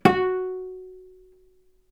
vc_pz-F#4-ff.AIF